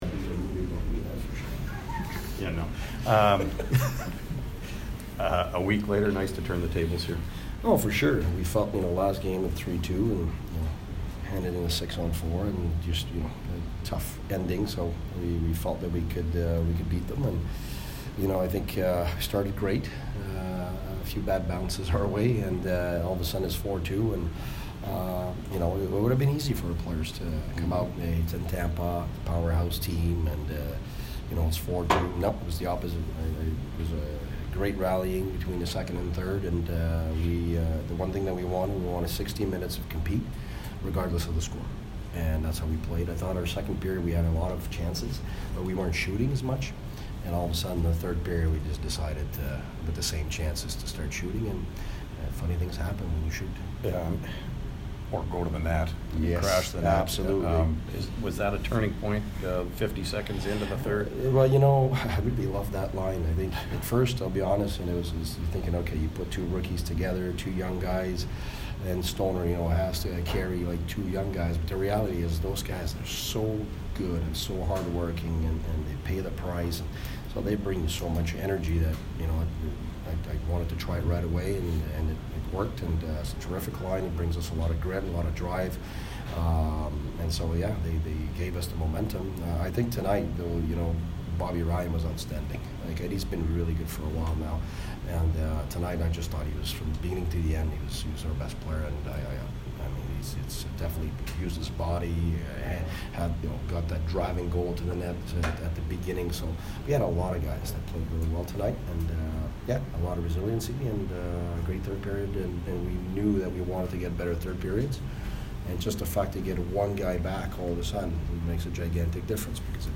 Guy Boucher post-game 11/10